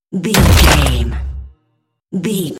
Dramatic deep hit bloody
Sound Effects
heavy
intense
dark
aggressive
hits